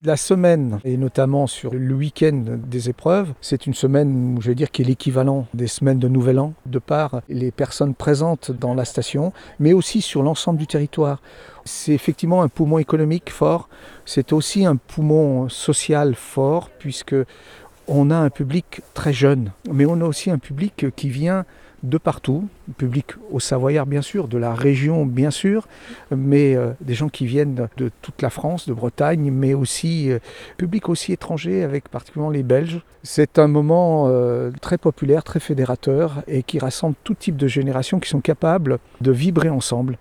L’évènement conserve donc un intérêt majeur pour plusieurs raisons expliquées par le maire du Grand-Bornand André Perrillat-Amédé.